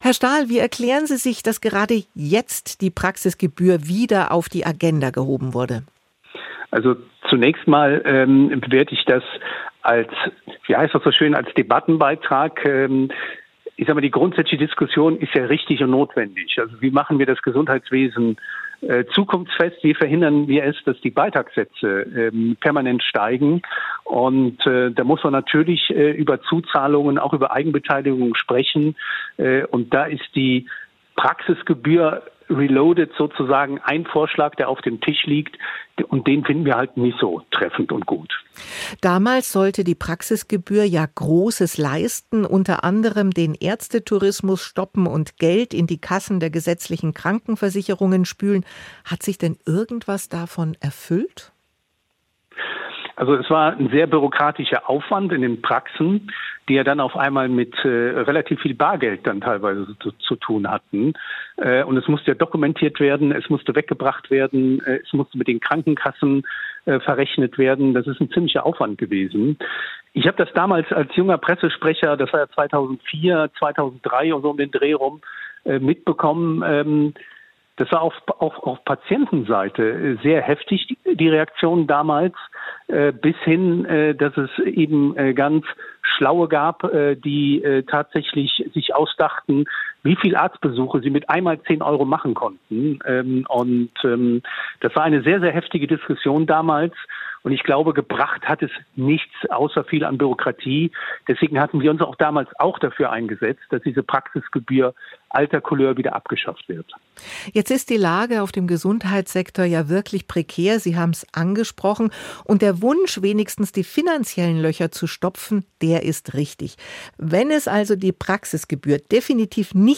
Aktuell-Interview.